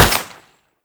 sfx_skill 03_2.wav